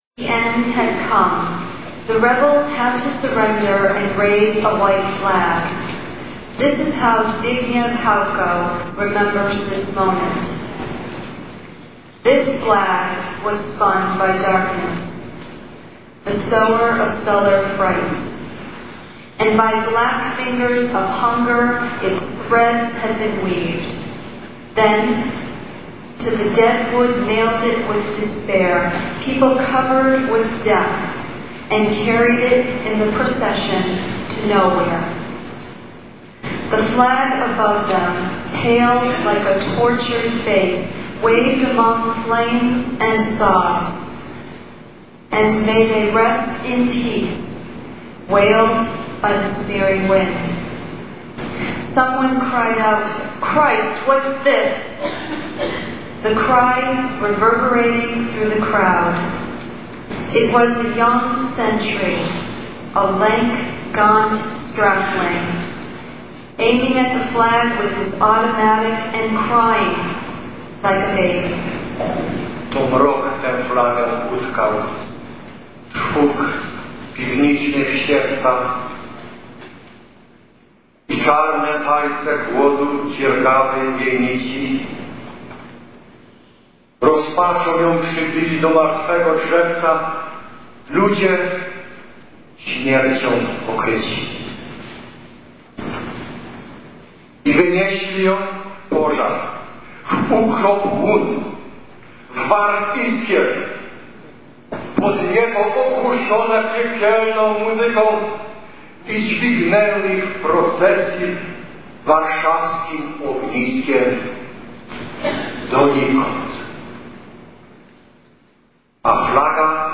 Presented on August 14th, 1994, at the St. Stanislaw's School in Chicopee, MA.
Keyboards, Accordion, and Musical Direction: